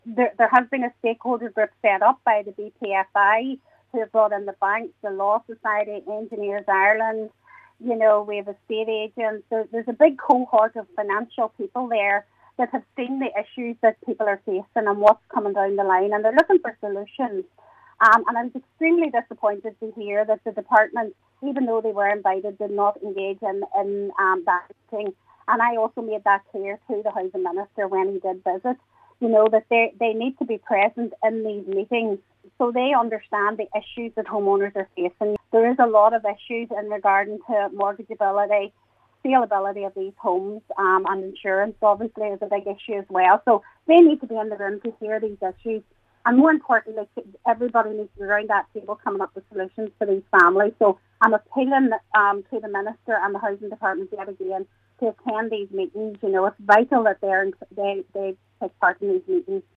Councillor Joy Beard says everyday families are falling further into uncertainty over their futures.
She has reiterated her call for representatives from the Department of Housing to attend a defective concrete block meeting: